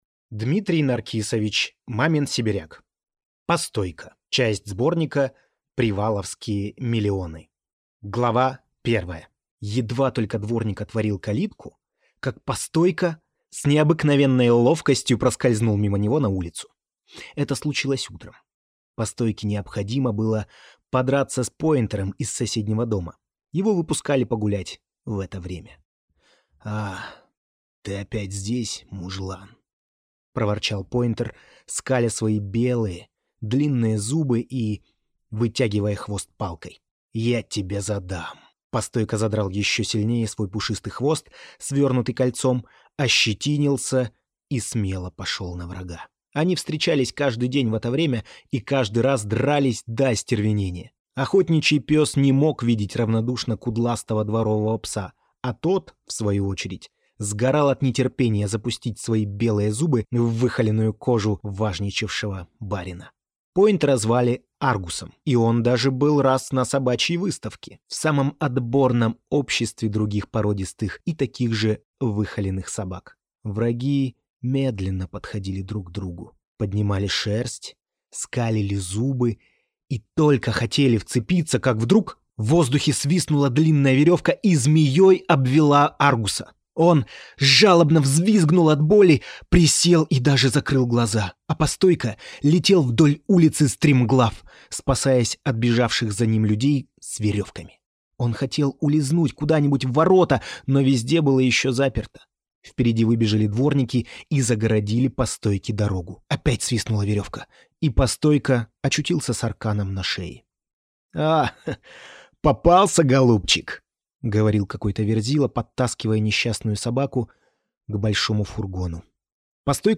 Аудиокнига Постойко